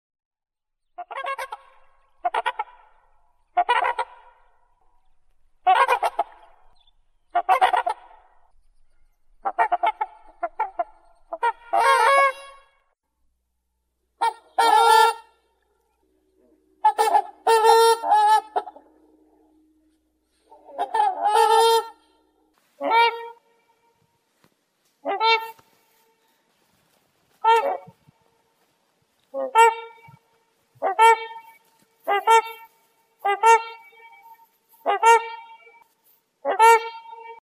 Звуки лебедей
Вы можете слушать или скачать их голоса, шум крыльев и плеск воды в высоком качестве.